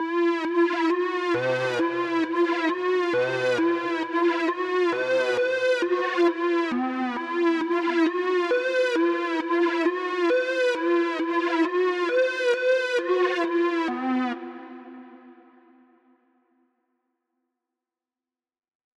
drill (!)_Omnisphere [64bit].wav